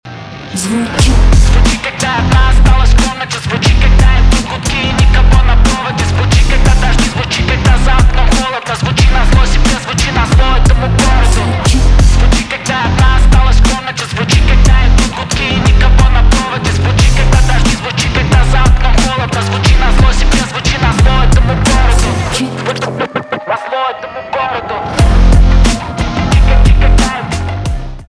поп
гитара , барабаны
качающие , басы